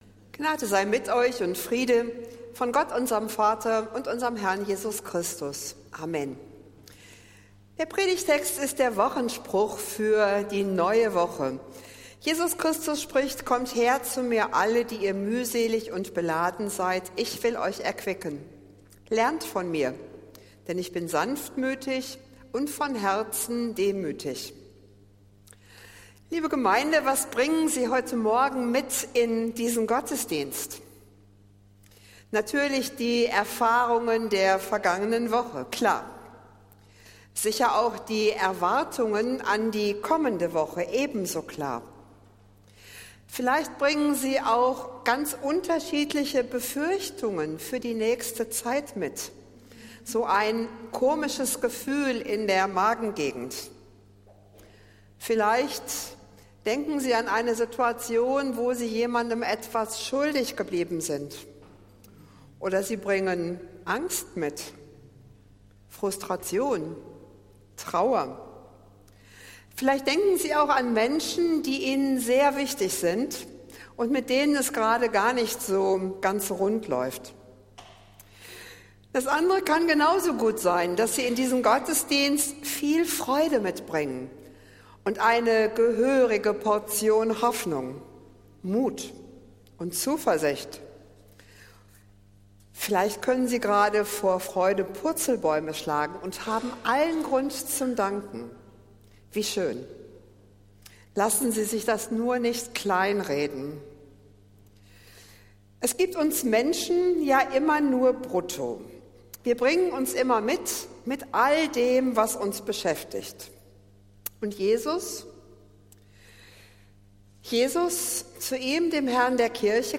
Predigt des Gottesdienstes aus der Zionskirche am Sonntag, den 9. Juni 2024